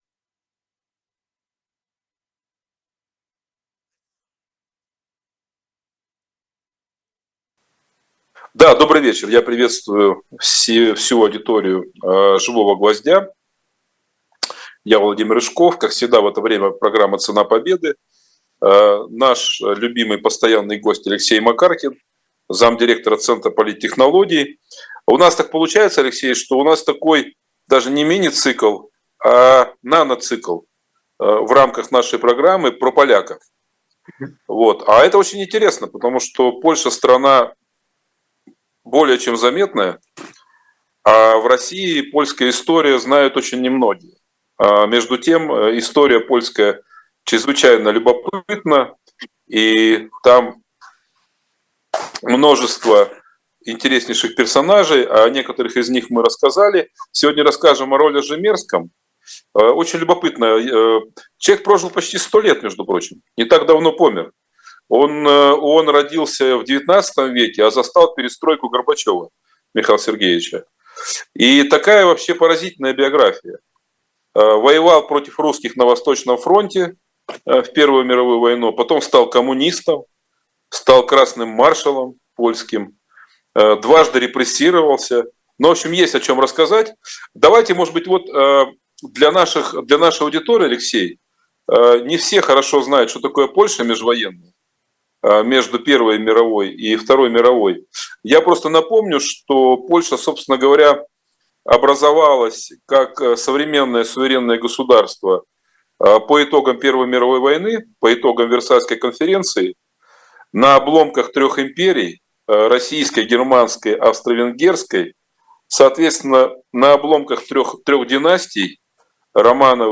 Эфир ведёт Владимир Рыжков.